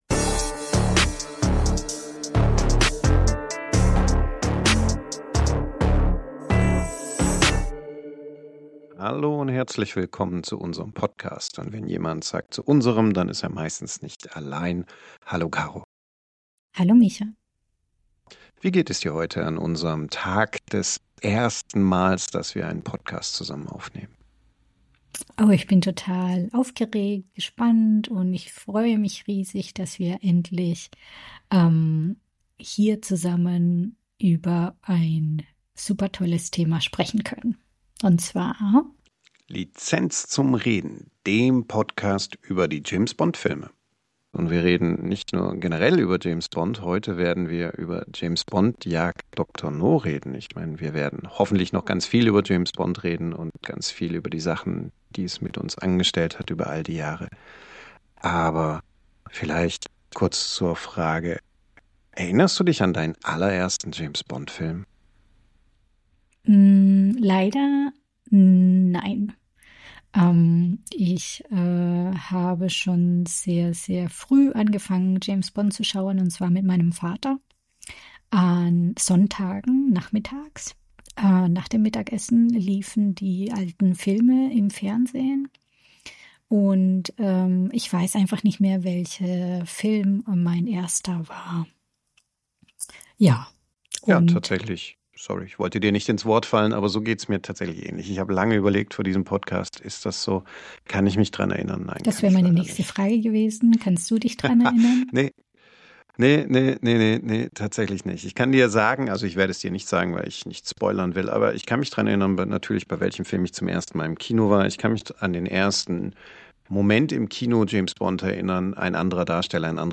Beschreibung vor 3 Monaten Mission Nummer eins: Dr. No. In der Auftaktfolge von Lizenz zum Reden nehmen wir uns den ersten James-Bond-Film vor und sprechen über den Startschuss der 007-Legende. Von Sean Connerys Debüt über ikonische Momente bis hin zu den Elementen, die James Bond bis heute prägen. Ein Gespräch über Filmgeschichte, Atmosphäre, erste Bond-Formeln und die Frage, warum Dr. No auch heute noch funktioniert.